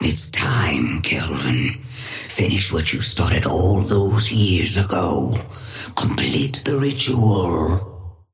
Sapphire Flame voice line - It's time, Kelvin, finish what you started all those years ago.
Patron_female_ally_kelvin_start_03_alt_01.mp3